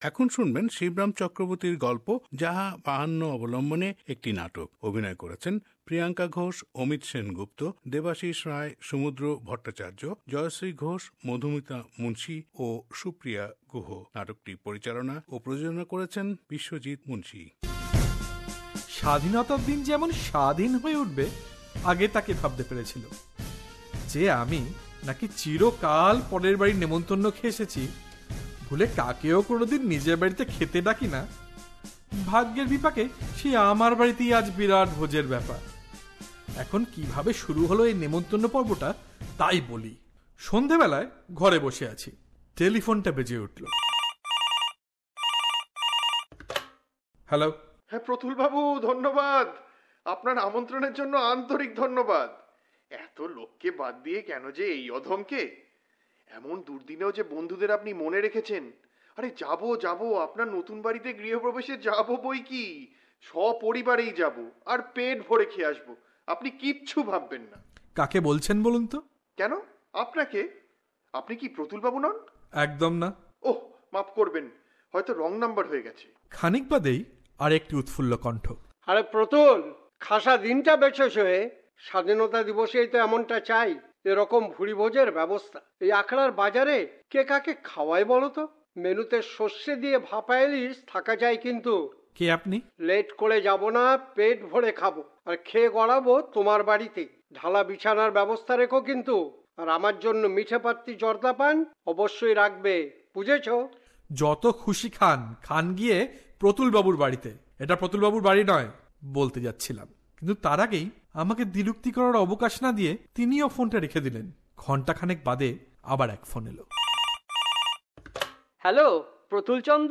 Bangla drama ‘Jaha Bahanno’ by popular writer Sibram Chakraborty